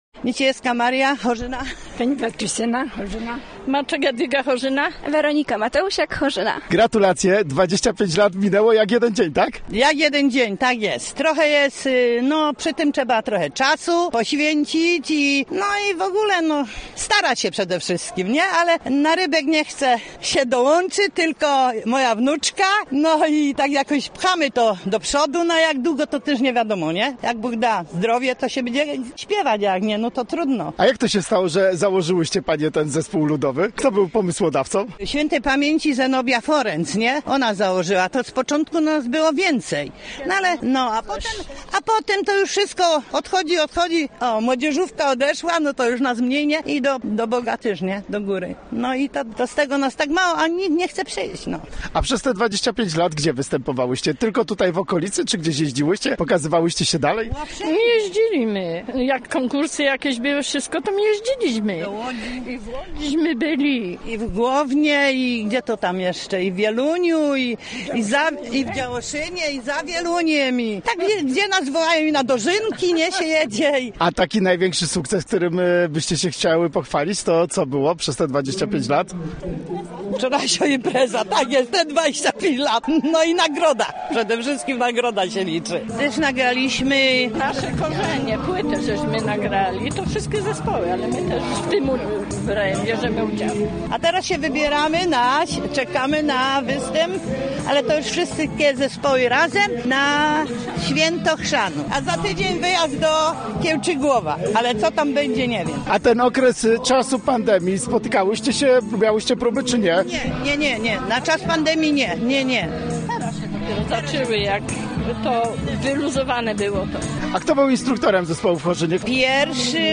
Gościem Radia ZW były członkinie Zespołu Pieśni Ludowej z Chorzyny